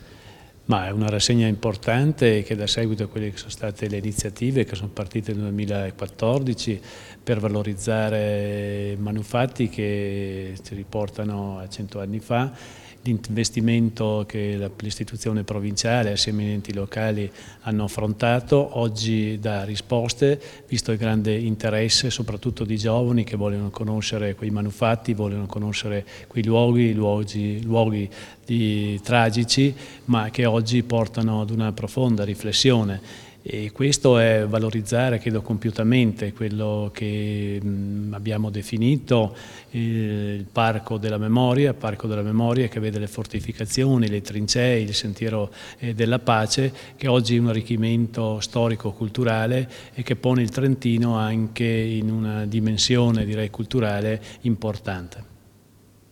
L'iniziativa è stata presentata questa mattina presso il Palazzo della Provincia, alla presenza dell'assessore provinciale alla cultura Tiziano Mellarini.